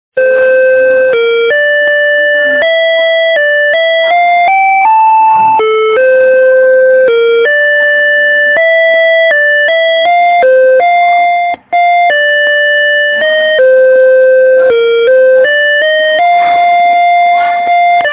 בקשות קליפים / לופים / אפקטים, ועוד - סאונד של צלצול בית ספר
הצלצול הצורם הזה... הרגיל.